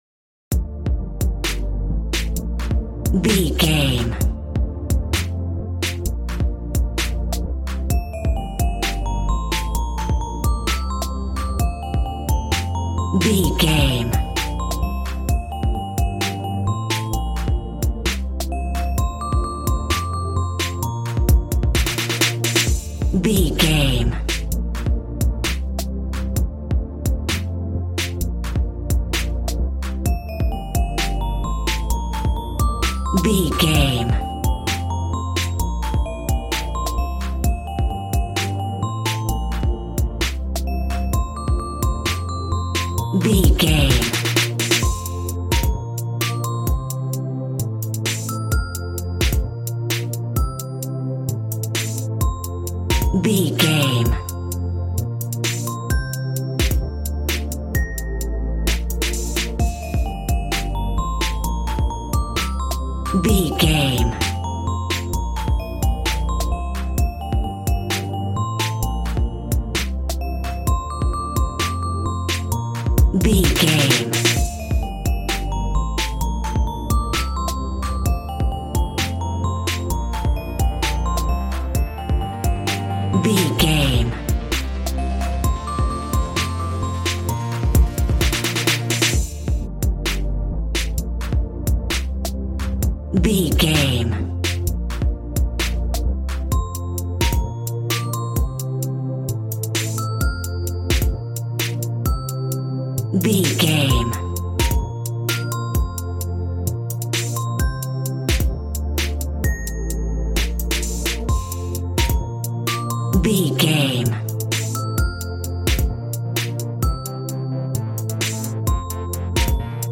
Aeolian/Minor
Fast
groovy
synthesiser
drums
cool
piano